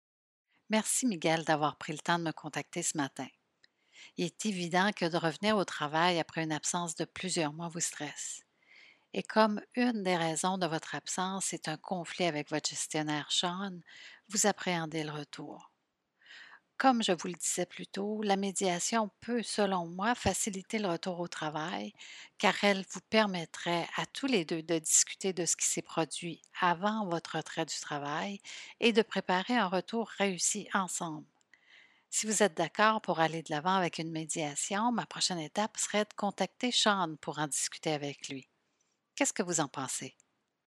Ces entretiens fictifs sont basés sur un amalgame d’expériences vécues.
un employé qui est préoccupé par son retour au travail.